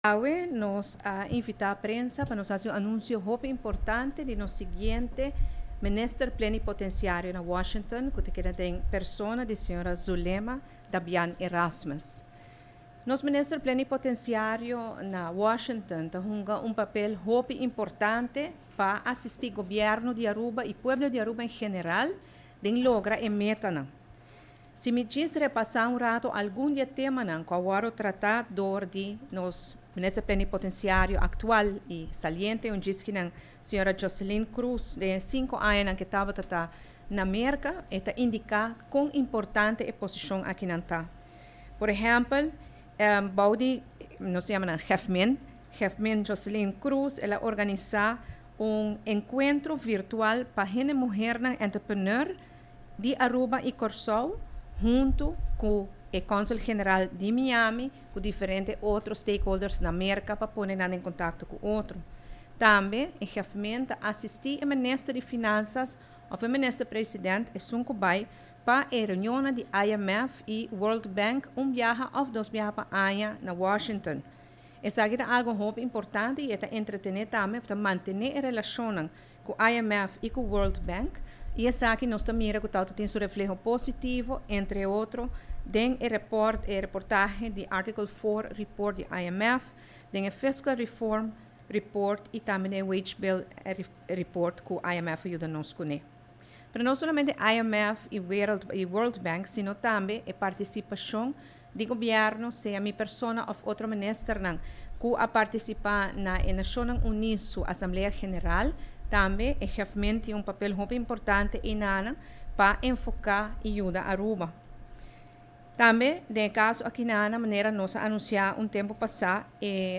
Dialuna atardi, durante conferencia di prensa di Gobierno, Prome Minister Evelyn Wever-Croes a anuncia cu e siguiente Minister Plenipotenciario pa Washington lo ta señora Zulema Dabian – Erasmus. Prome Minister a splica cu e posicion aki ta uno importante, mirando cu e funcionario aki, lo asisti Gobierno di Aruba y pueblo en general pa logra cierto metanan, manera señora Joselin Croes a haci durante su añanan como Minister Plenipotenciario na Washington.